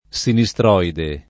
[ S ini S tr 0 ide ]